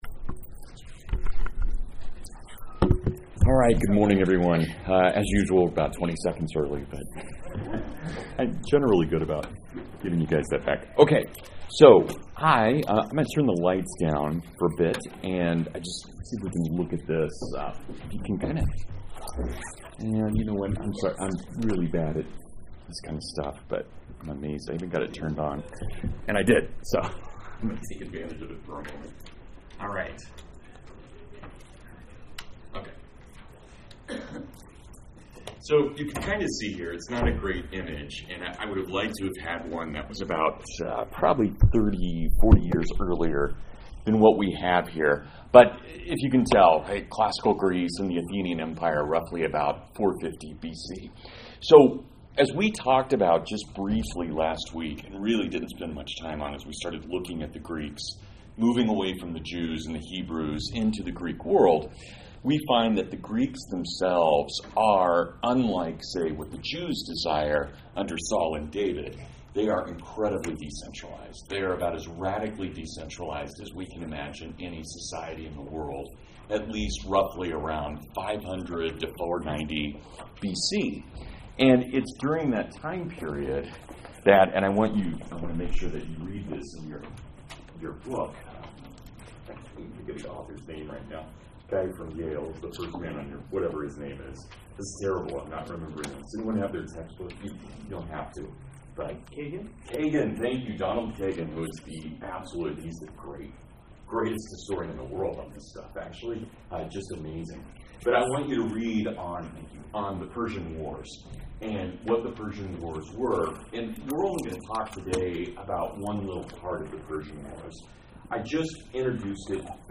Leonidas and 300 Spartans birth western civilization through their sacrifice. Complete with not one but TWO tornado sirens during the lecture.